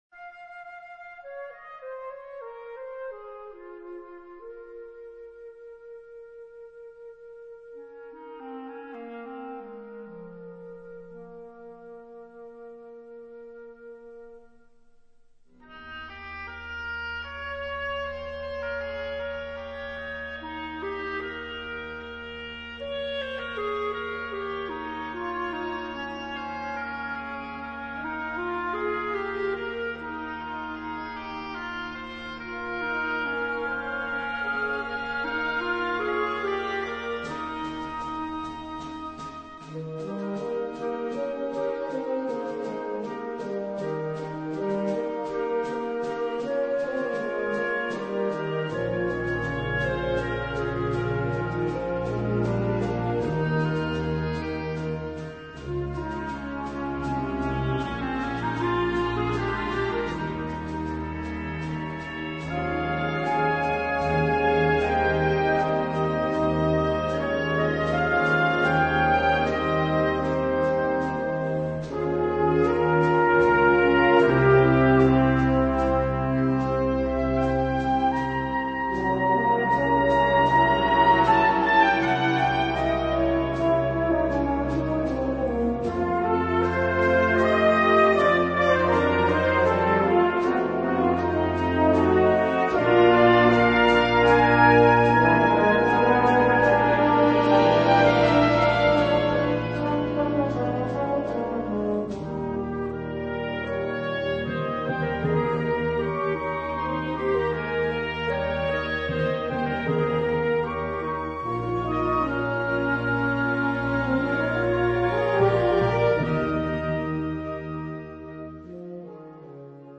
Category Concert/wind/brass band
Subcategory Concert music
Instrumentation Ha (concert/wind band)